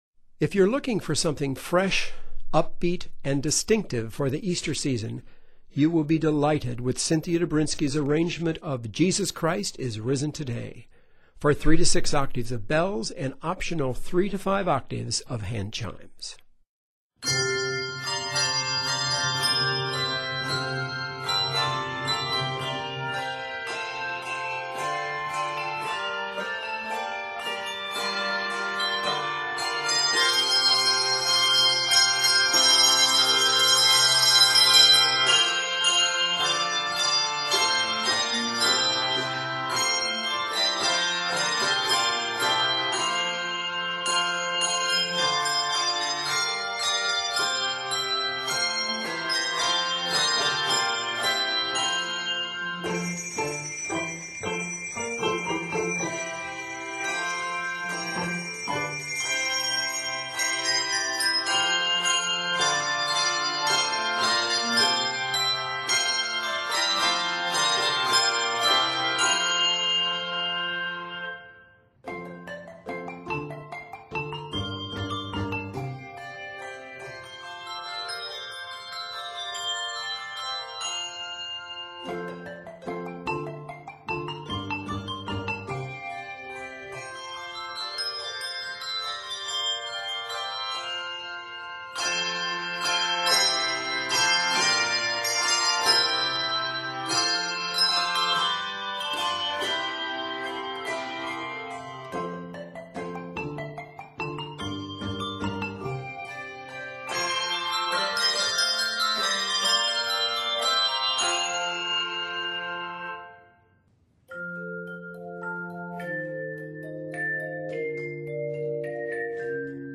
joyful and creative rendition